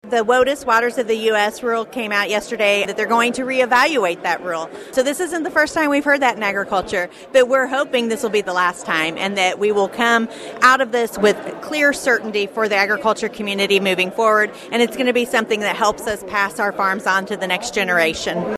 25-0313-chinn-speaking-at-kmmo-ag-day
During her remarks at KMMO Ag Day on the Mull Family Farm outside Malta Bend on Thursday, March 13, Missouri Director of Agriculture Chris Chinn updated attendees on a pair of developments from Washington.